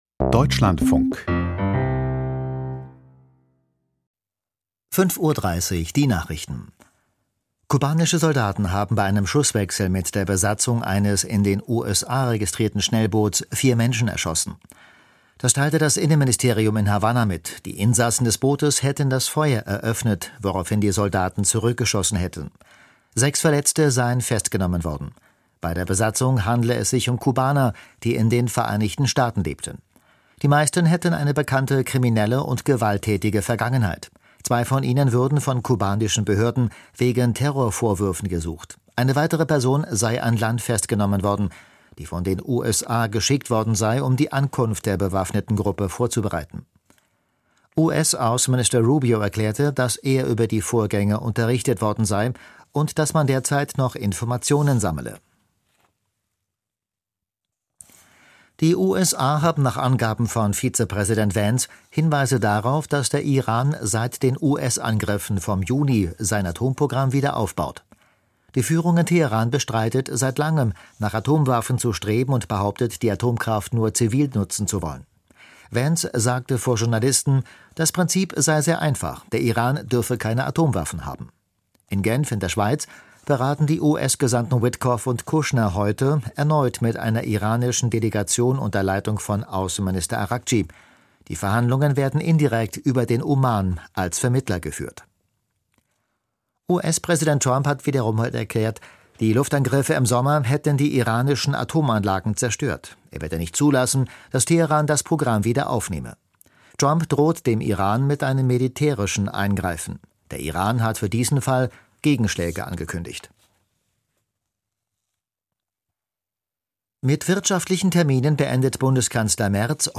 Die Nachrichten vom 26.02.2026, 05:30 Uhr
Aus der Deutschlandfunk-Nachrichtenredaktion.